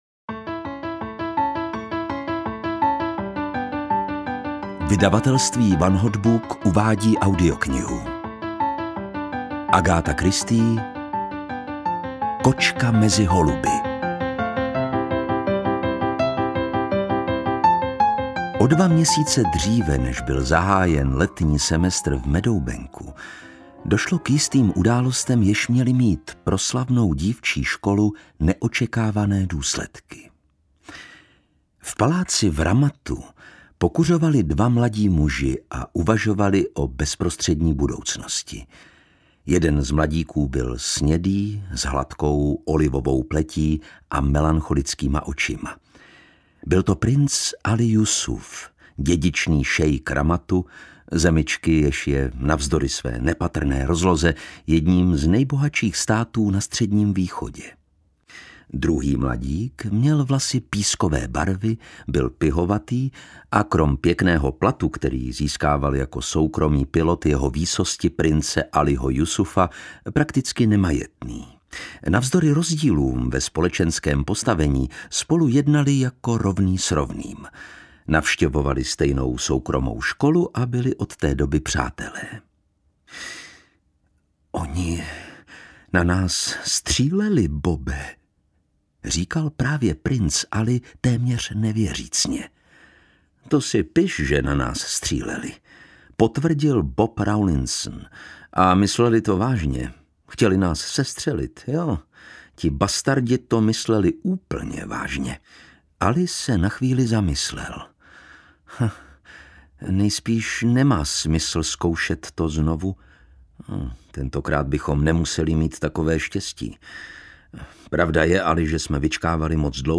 Kočka mezi holuby audiokniha
Ukázka z knihy